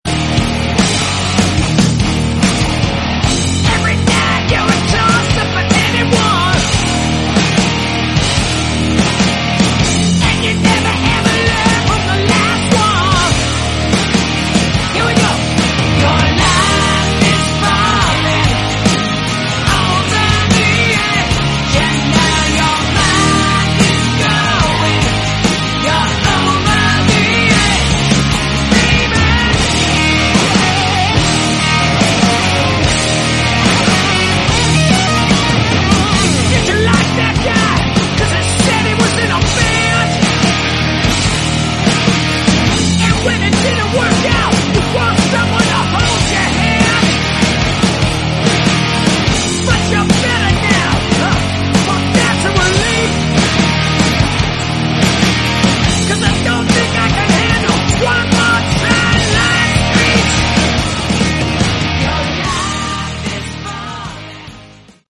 Category: Rock
vocals, guitar, bass
bass guitar